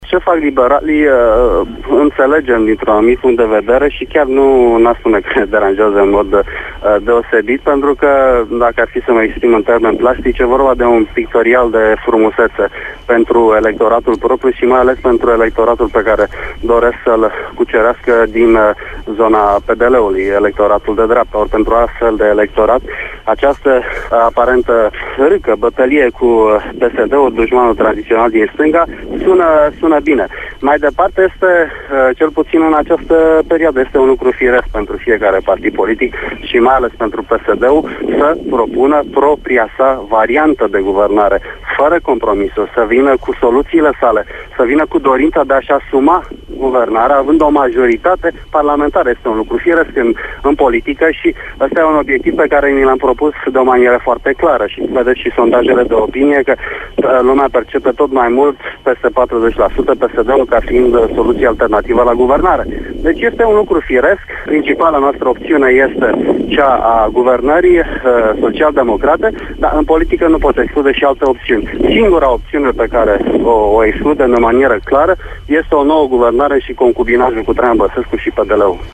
„Liberalii fac un pictorial de frumusete pentru electoratul de dreapta”, a declarat la RFI de vice-presedintele PSD, Titus Corlatean. El a spus ca intelege atitudinea PNL, care transmite mesaje anti-PSD, pentru a cuceri noi alegatori din zona dreptei, mai exact din randul alegatorilor Partidului Democrat Liberal.